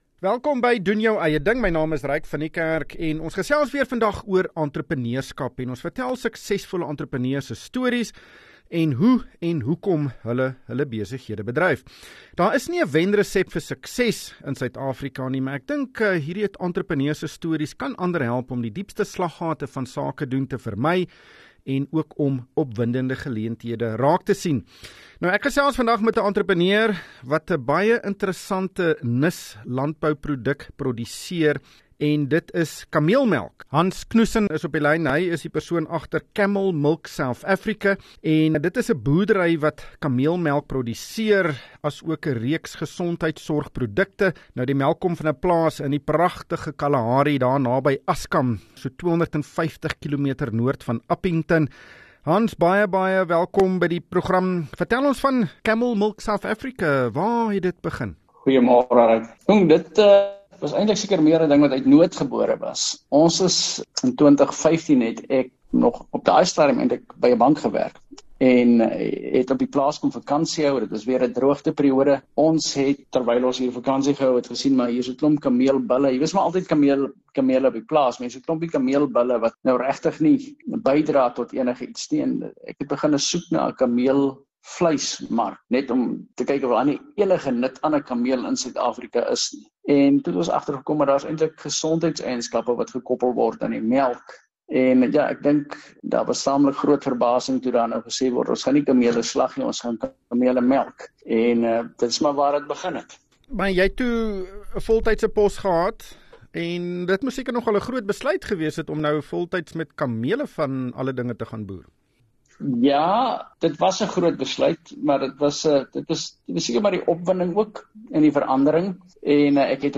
Wees deel van die gesprek met beleggingskenners en finansiële gurus in ateljee.